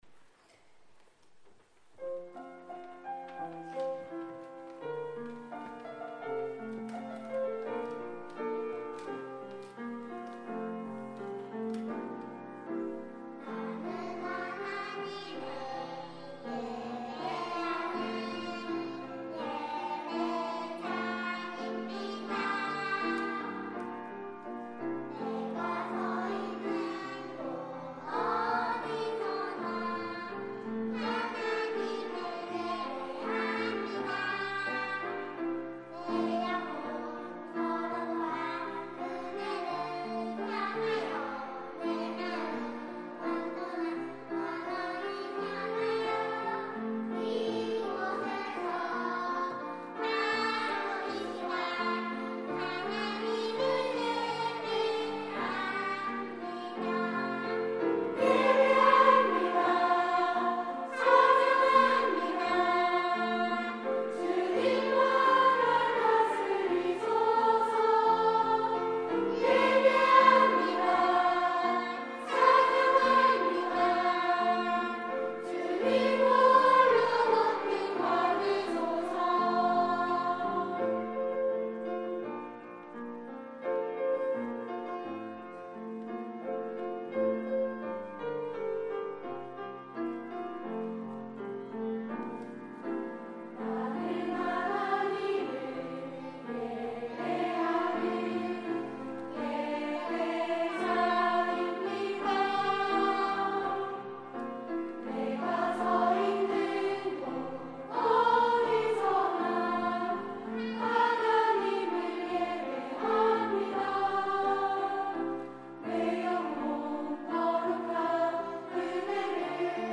온세대예배 찬양대